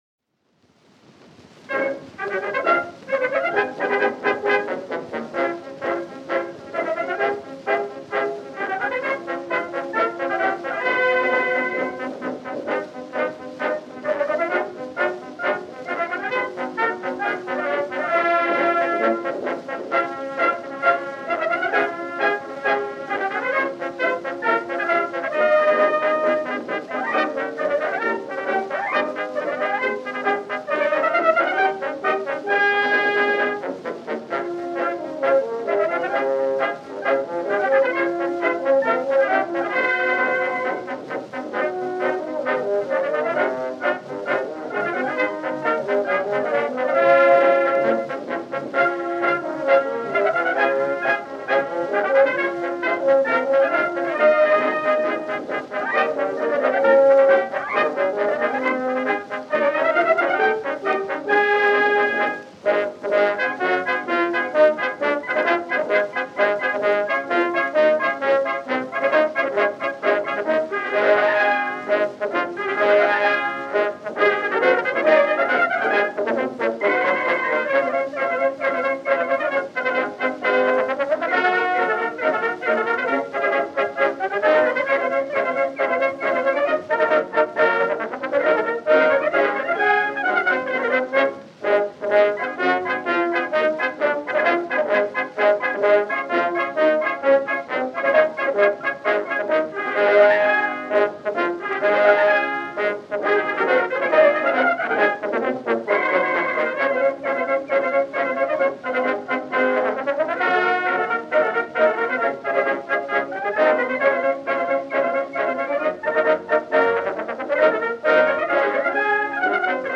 March